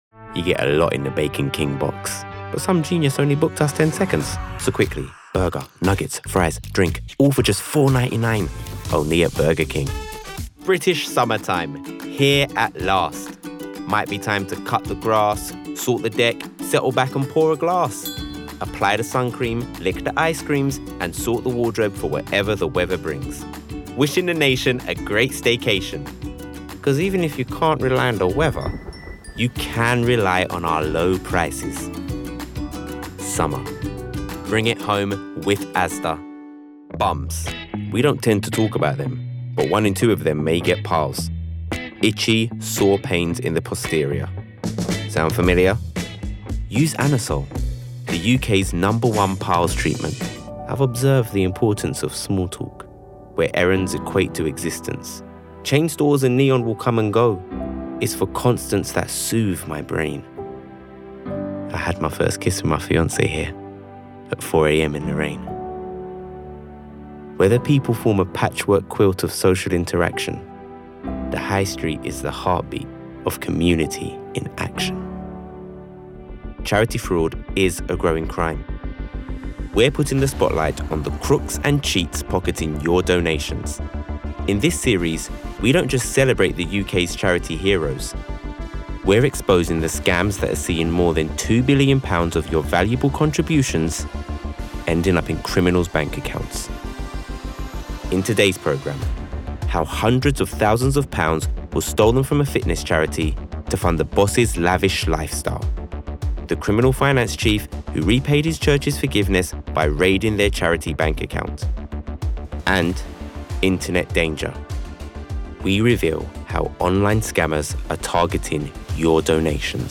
Native London. Cool, clear and confident.
• Male
Showreel Compilation